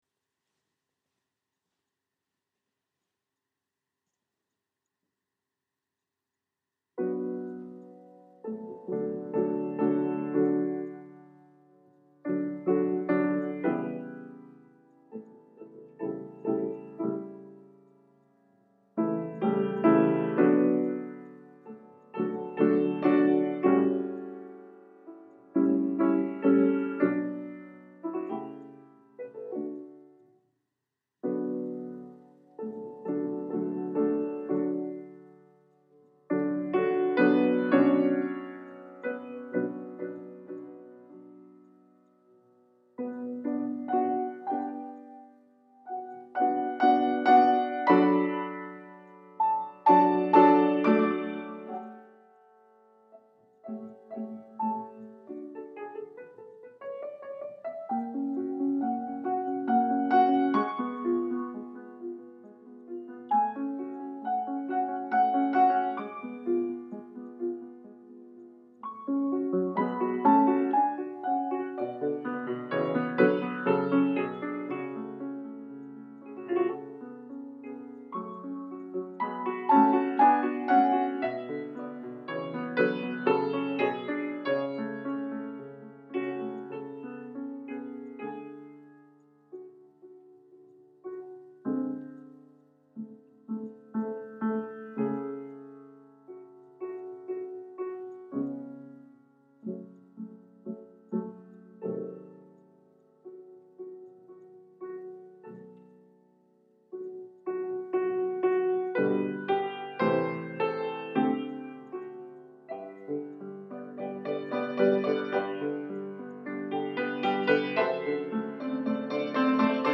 Movement II from Sonata in A Major